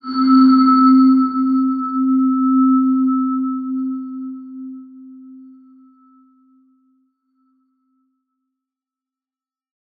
X_BasicBells-C2-pp.wav